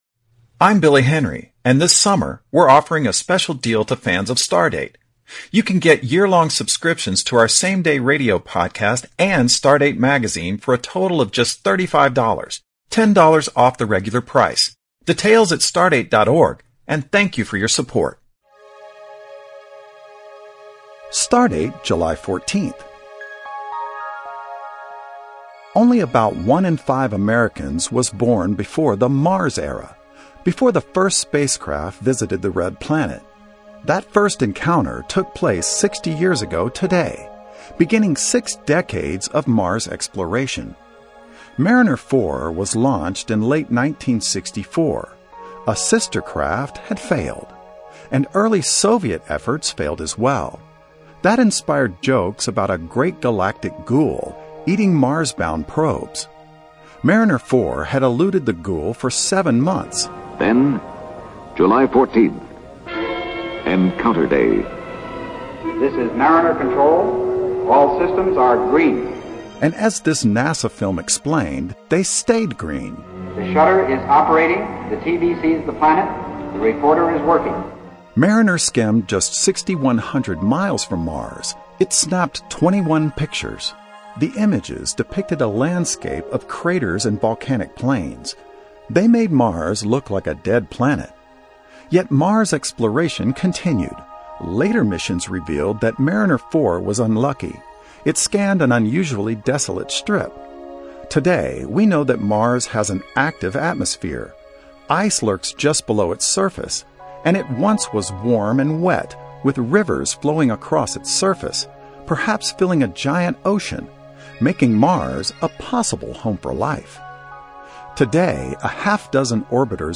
StarDate, the longest-running national radio science feature in the U.S., tells listeners what to look for in the night sky.